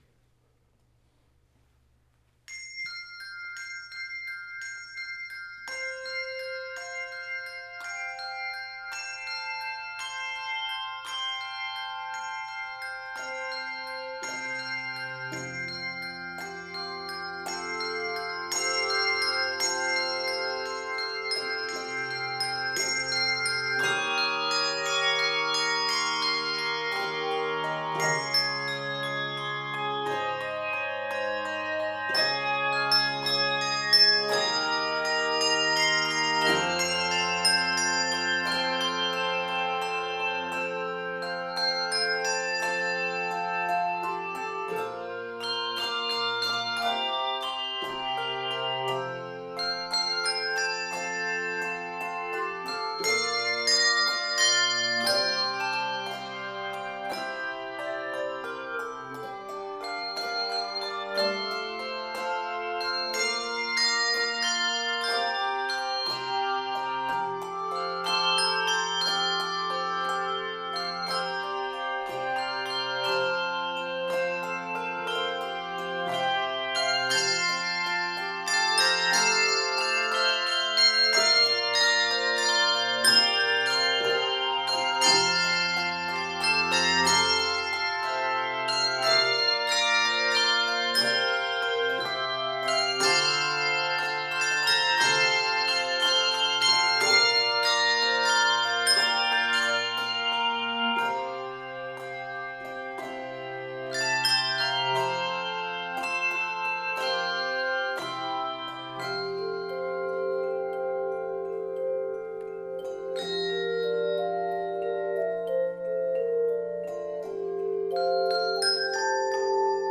Voicing: Handbells 3-7 Octave